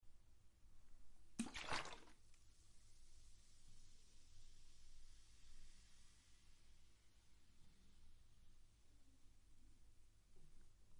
苏打水打开后倒入
描述：打开一瓶碳酸苏打水，倒入玻璃杯中。
Tag: 嘶嘶声 打开 倒出 苏打水 闪闪发光 玻璃 倒水 汽水 饮料 可乐 打开 液体 sodapop 碳酸